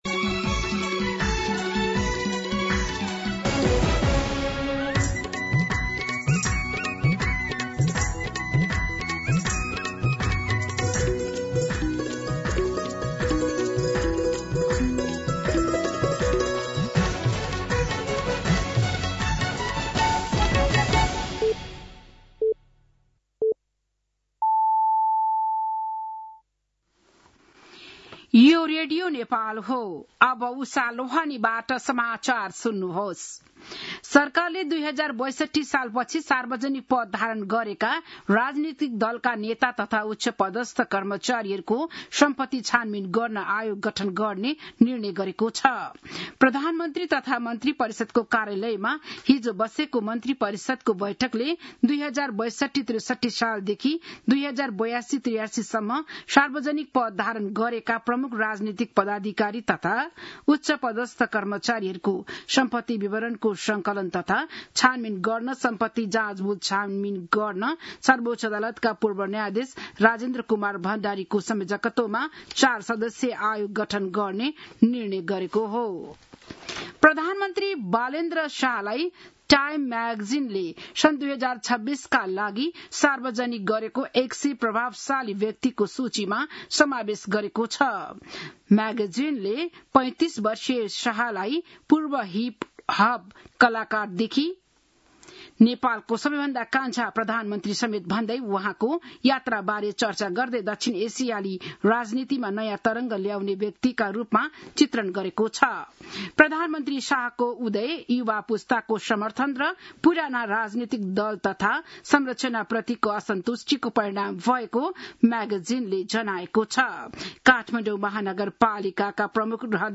बिहान ११ बजेको नेपाली समाचार : ३ वैशाख , २०८३
11-am-Nepali-News.mp3